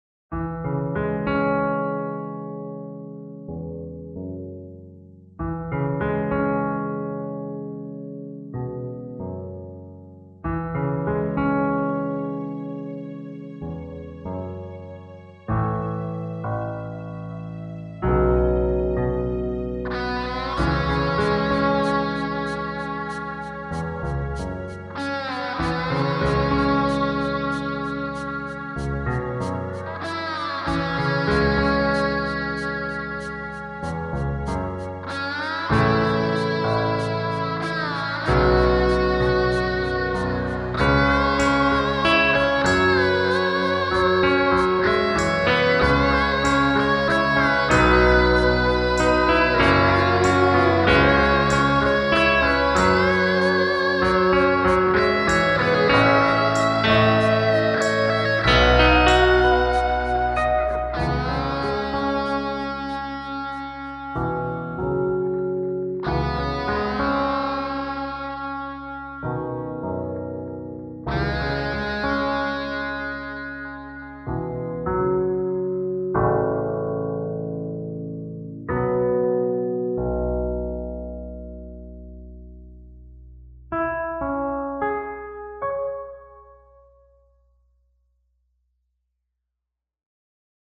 a soundtrack for a fictitious movie